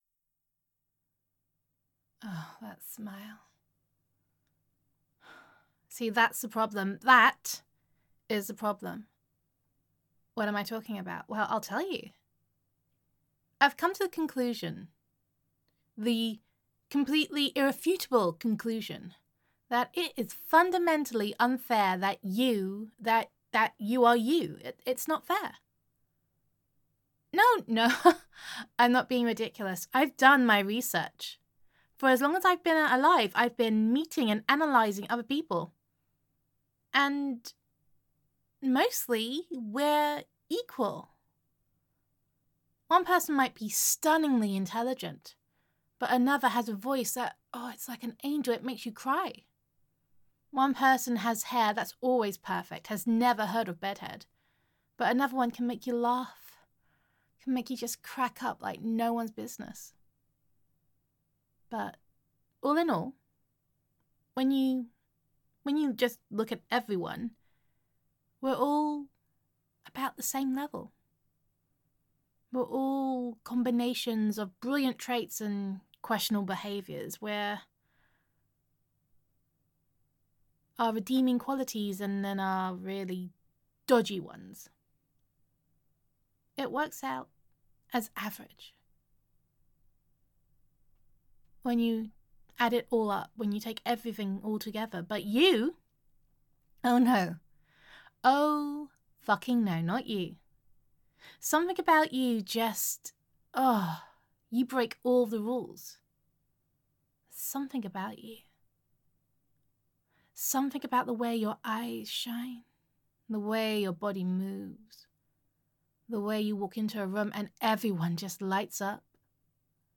Downloads Download F4A_You_Fuck_Me_up_I_Do_Not_Have_a_Crush_on_YouDenialIndignationIt_Is_Not_FairPoutingAdorable_-_Patreon.mp3 Content [F4A] You Fuck Me up [I Do Not Have a Crush on You][Denial][Indignation][It Is Not Fair][Pouting][Adorable]